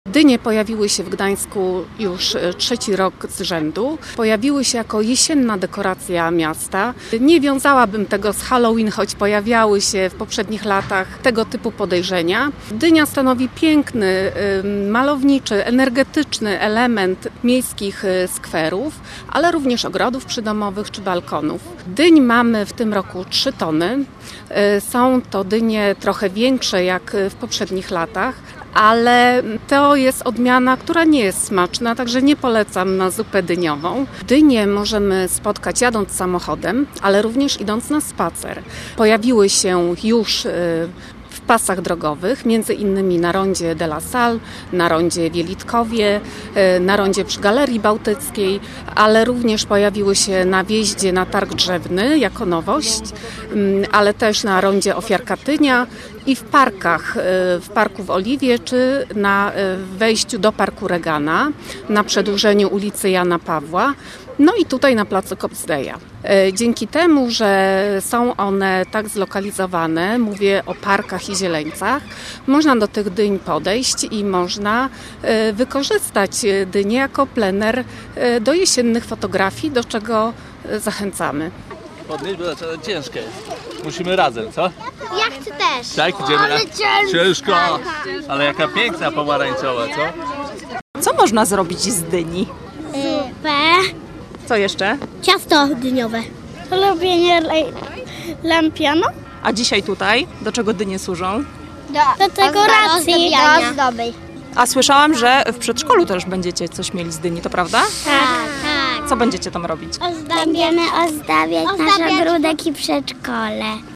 Posłuchaj materiału naszej reporterki.